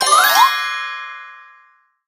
gray_teleporting_01.ogg